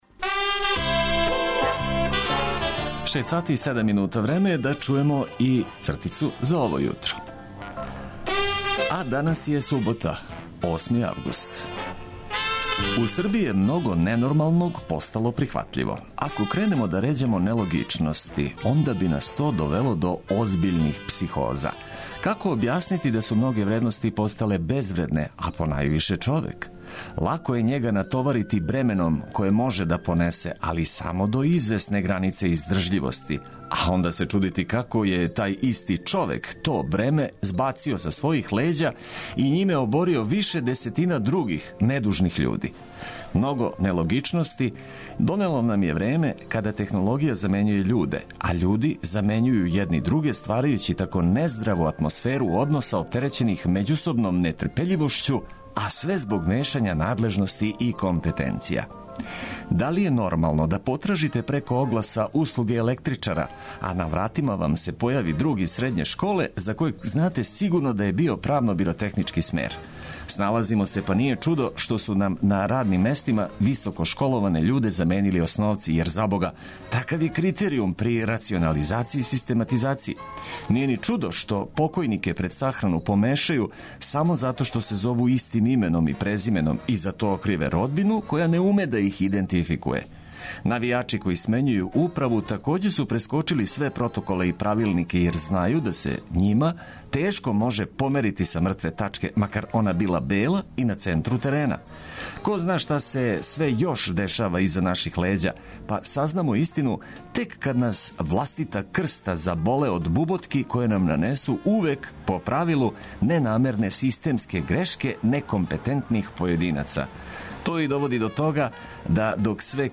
Радијско суботње разбуђивање уз подстрек за лагано напуштање постеље и преузимање одговорности за нови дан.